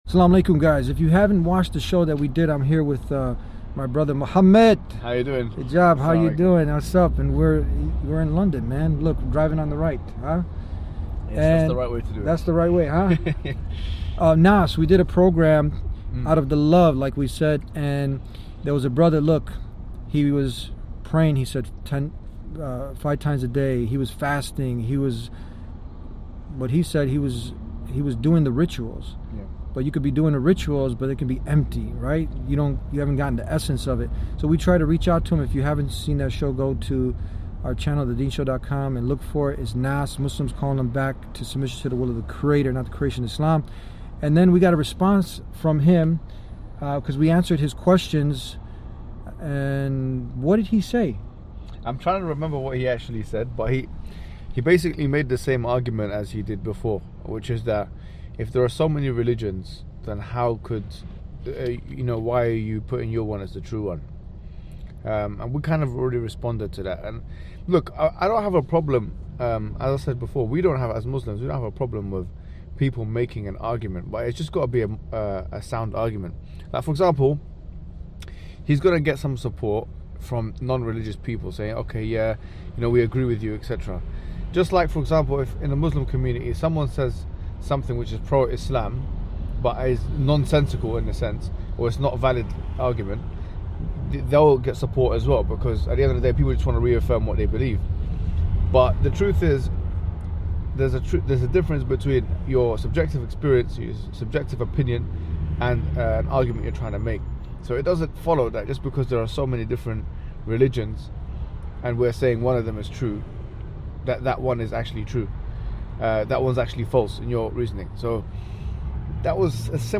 in a conversation with Mohammed Hijab on The Deen Show
in London